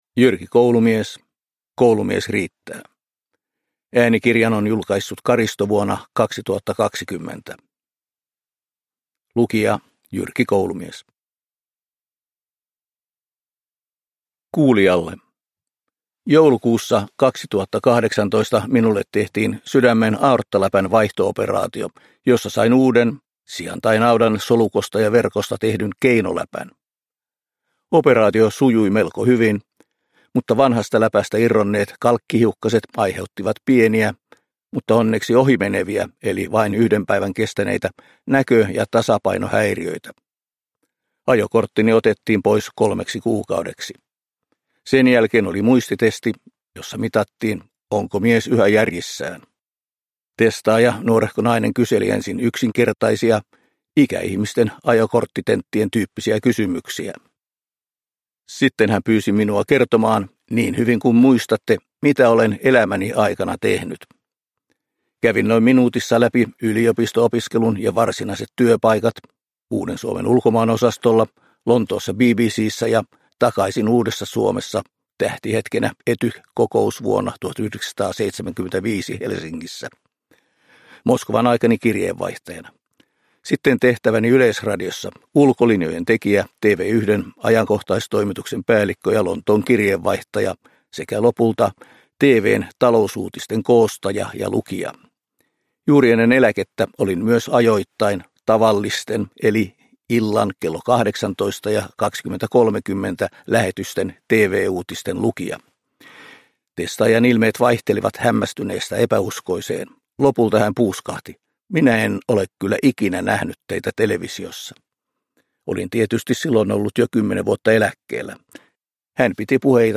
Produkttyp: Digitala böcker
Uppläsare: Jyrki Koulumies